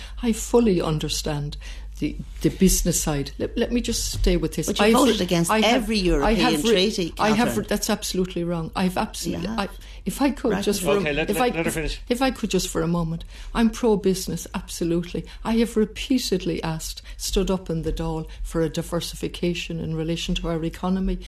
Both candidates spoke of previous treaties voted on within the EU: